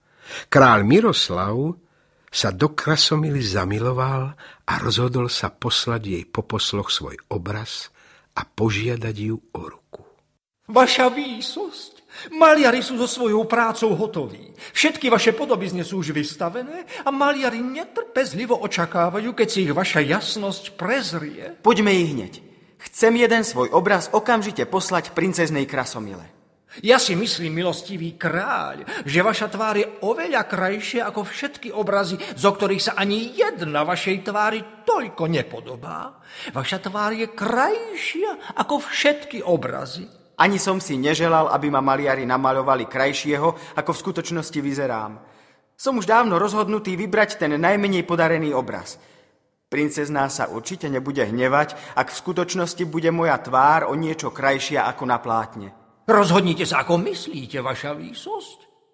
NAJKRAJŠIE ROZPRÁVKY 1 - Princ Bajaja & Potrestaná pýcha - Různí autoři - Audiokniha
• Čte: Petr Pospíchal, Marian Labuda, Hana…